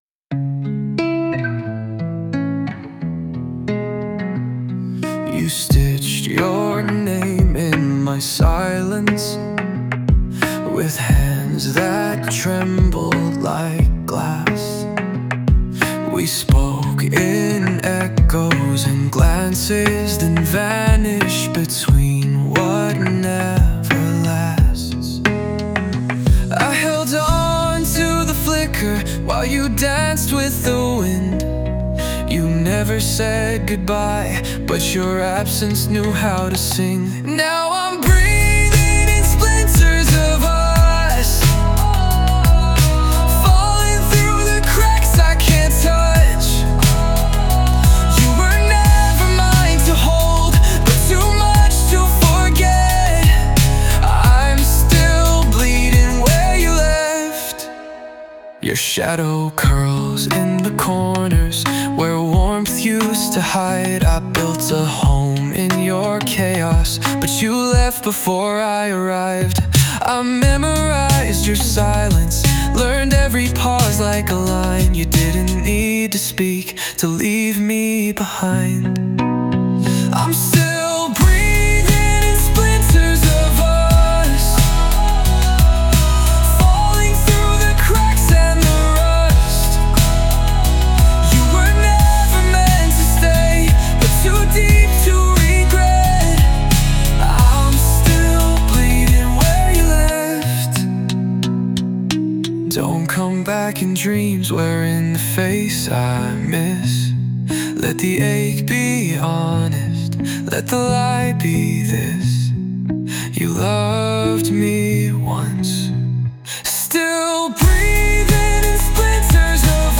洋楽男性ボーカル著作権フリーBGM ボーカル
男性ボーカル洋楽洋楽 男性ボーカルバラード静か切ない
著作権フリーオリジナルBGMです。
男性ボーカル（邦楽・日本語）曲です。